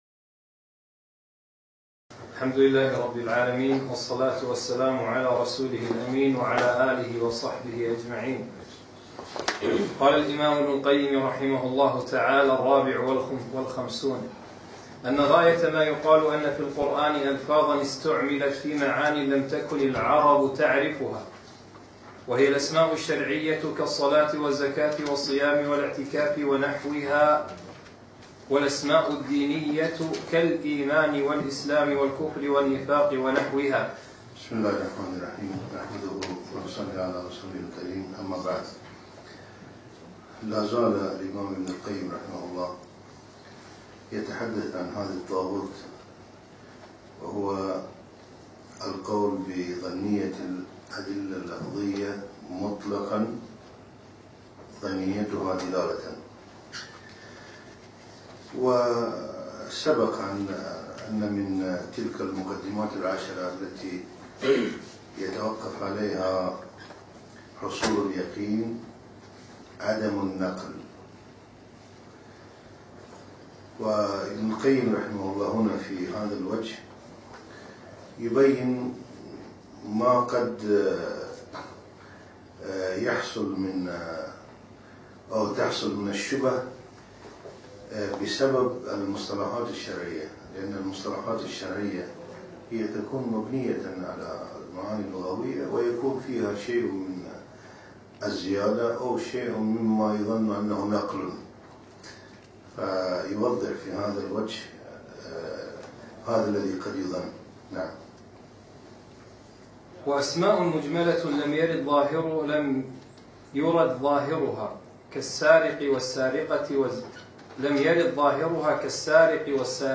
أقيم الدرس في ديوان مشروع الدين الخالص يوم الأربعاء 1 4 2015